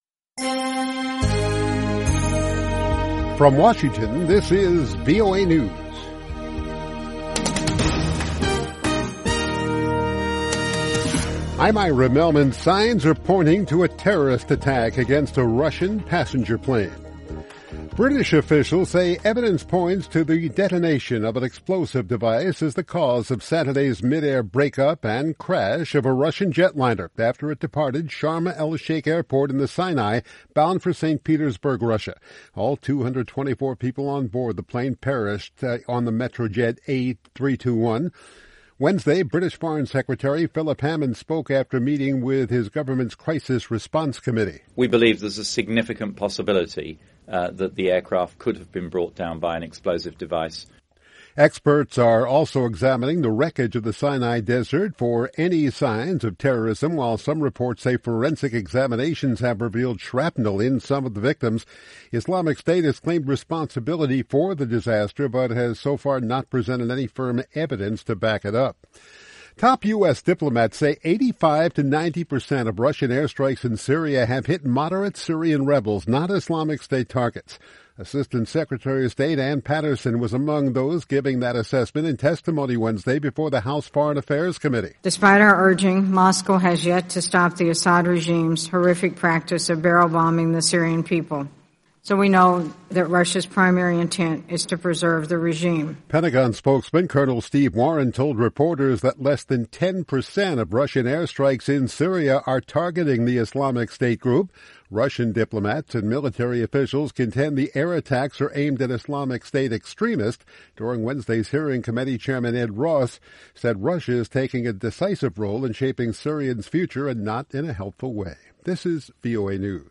VOA English Newscast 1600 UTC November 5, 2015